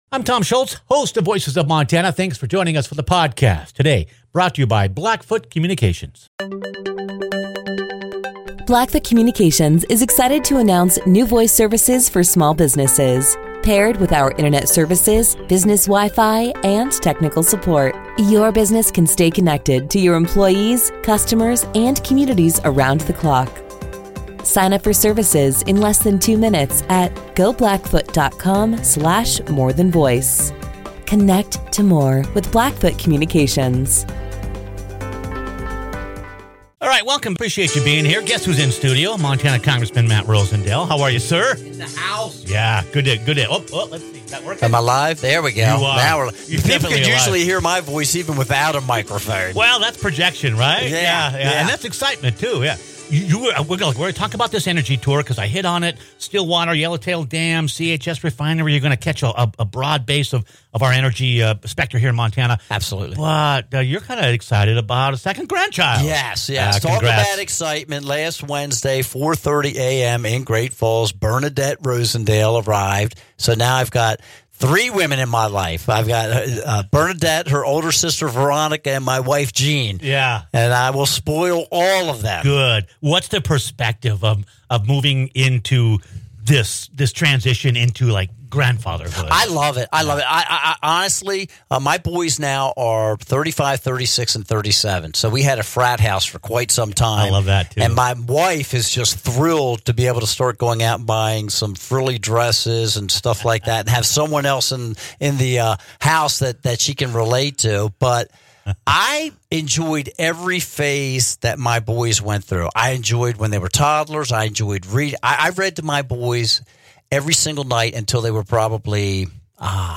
Eastern District Congressman Matt Rosendale stopped by the studio for a discussion on energy and spending ahead of a statewide energy tour.